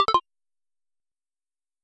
SFX_UI_Unequip.mp3